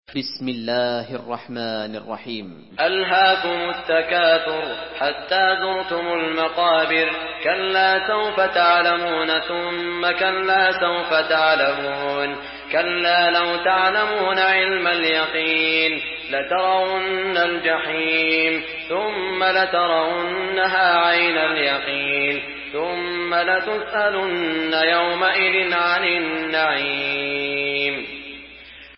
Surah At-Takathur MP3 by Saud Al Shuraim in Hafs An Asim narration.
Murattal